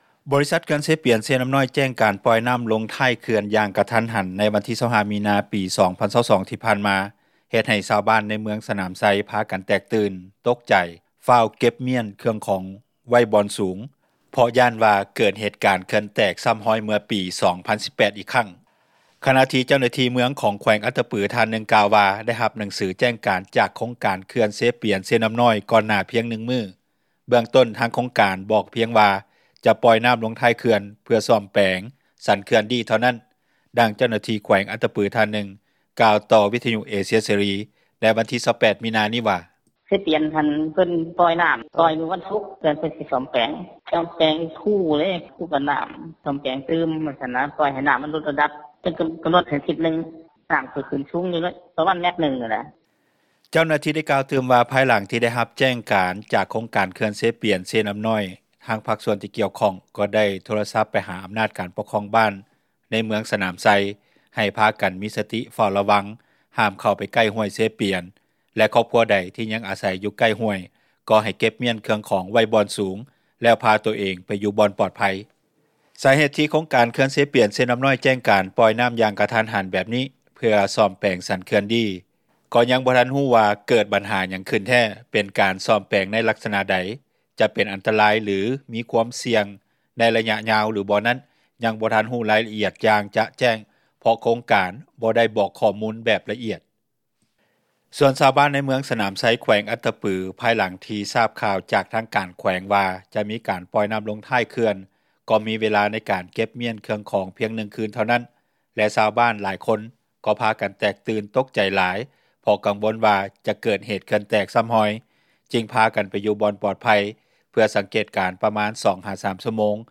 ດັ່ງເຈົ້າໜ້າທີ່ແຂວງອັດຕະປື ທ່ານນຶ່ງກ່າວຕໍ່ ວິທຍຸເອເຊັຽເສຣີ ໃນມື້ວັນທີ 28 ມີນານີ້ວ່າ:
ດັ່ງຊາວບ້ານຜູ້ນຶ່ງໃນເມືອງສນາມໄຊ ແຂວງອັດຕະປືກ່າວ ຕໍ່ວິທຍຸເອເຊັຽເສຣີ ໃນວັນທີ 28 ມີນານີ້ວ່າ: